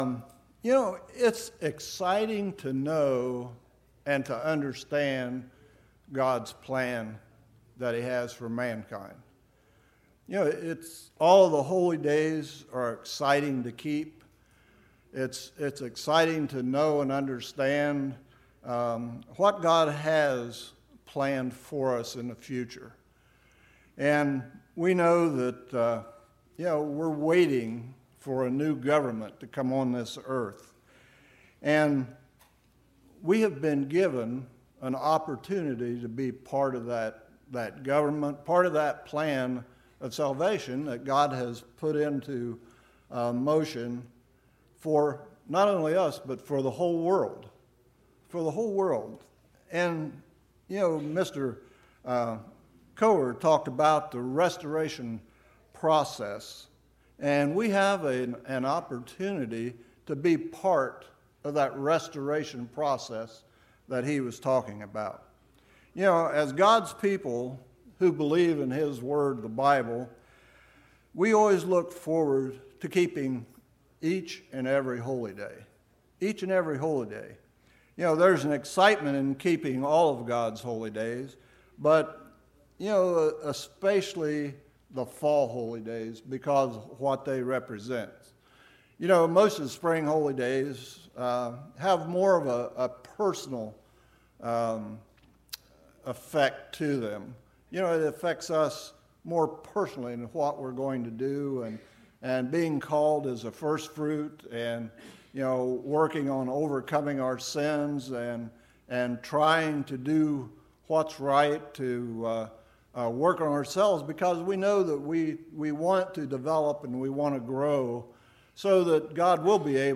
This sermon was given at the Lake George, New York 2018 Feast site.